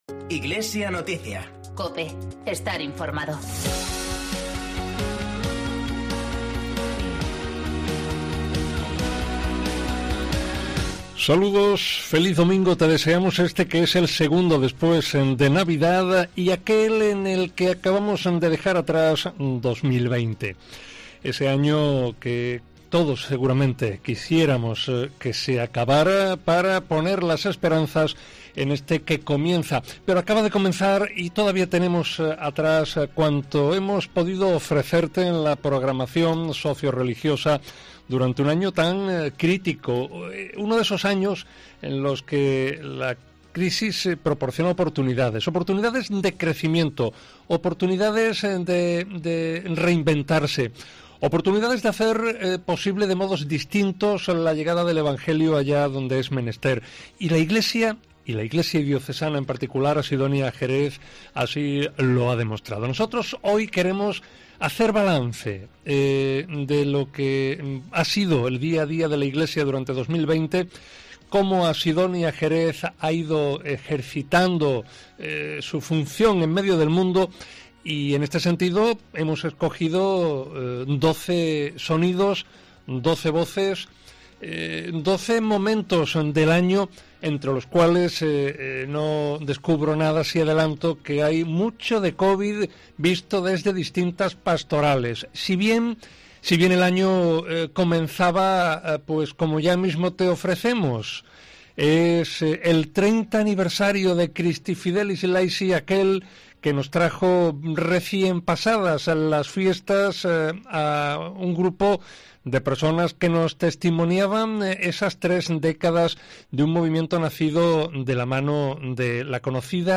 Han sido doce meses intensos y doce voces lo testimonian en este balance realizado en Iglesia Noticias emitido este domingo 3 de enero .